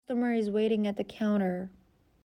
تلفظ با سرعت‌های مختلف